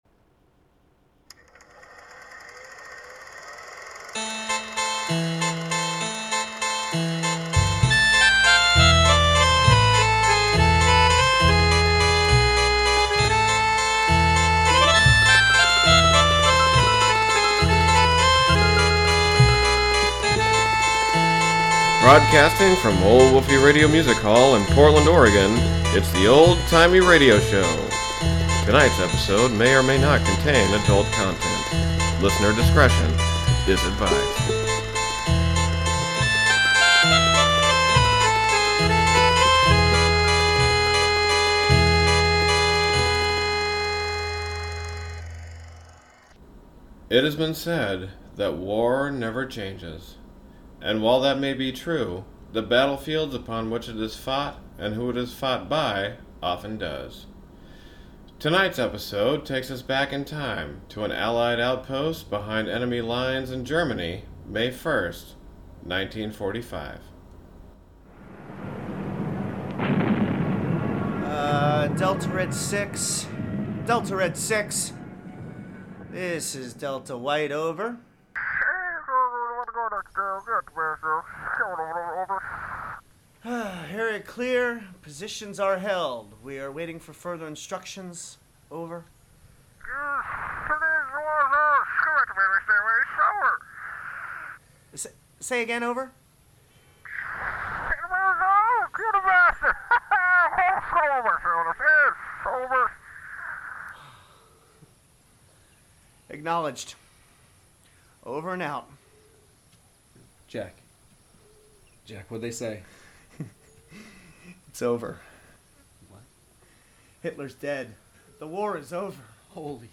The Old Timey Radio Show harkens back to the days of radio dramas, but with a bit of a sinister twist. Featuring all original scripts and music. With a great team of writers and voice talent, we deliver a good time in each episode.